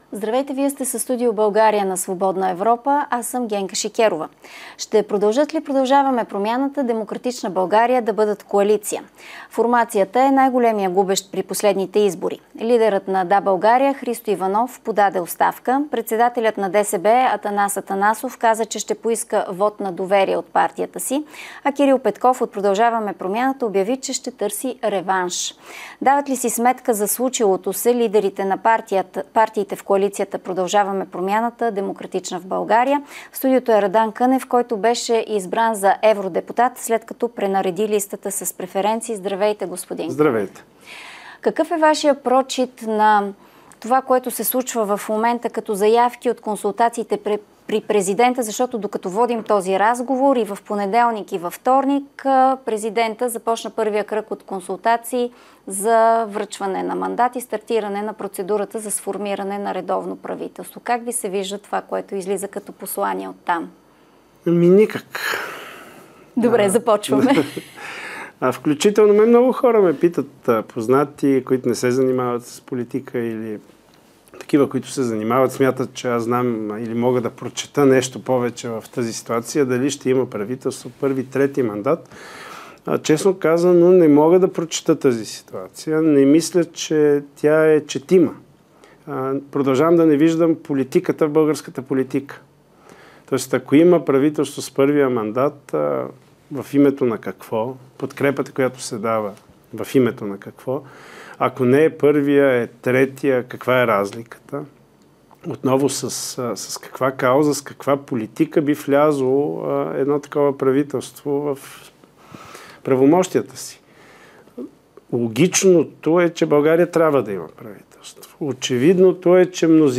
говори евродепутатът от ПП-ДБ Радан Кънев.